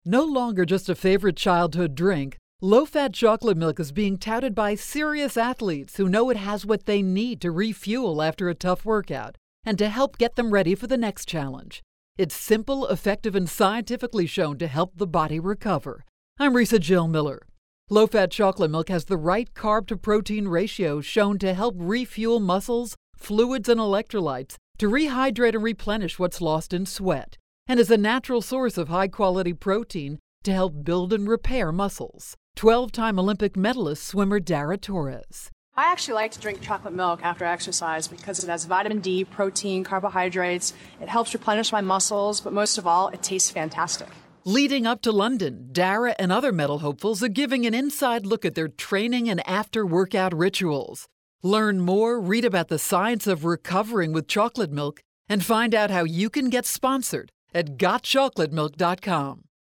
March 12, 2012Posted in: Audio News Release